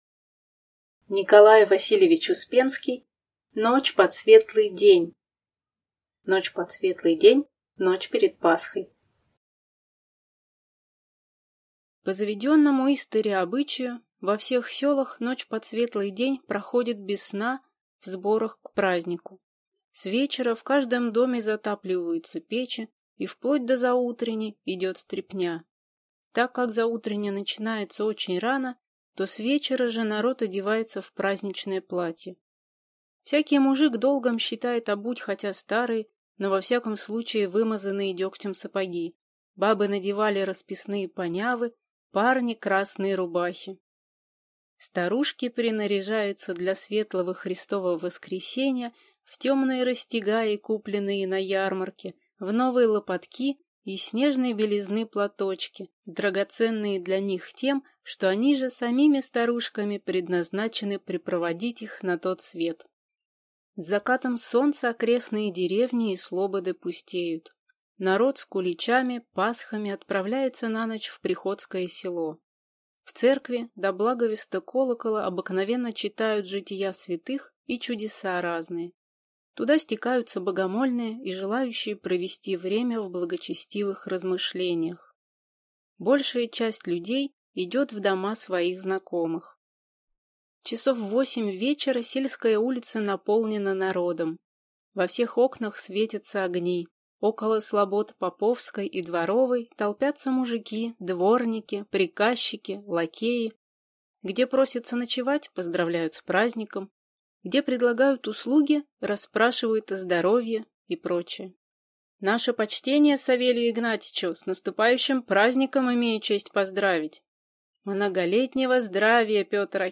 Аудиокнига Ночь под светлый день | Библиотека аудиокниг